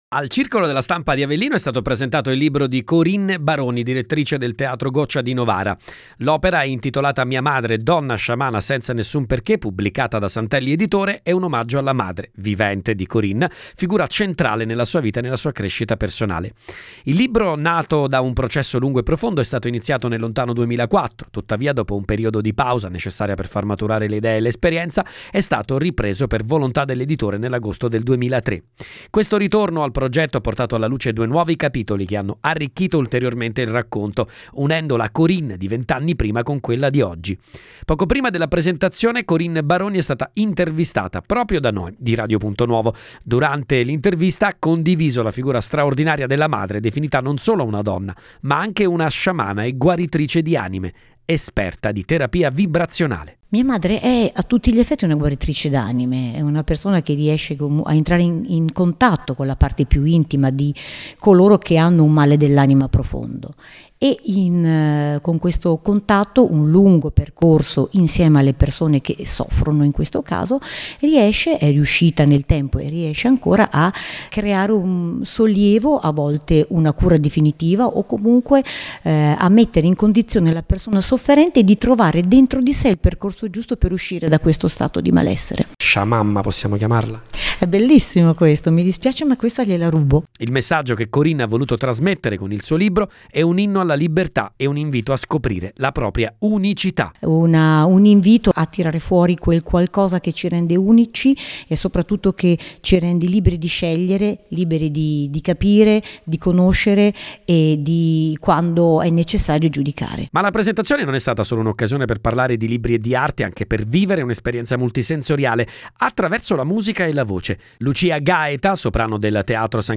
è stata intervistata da Radio Punto Nuovo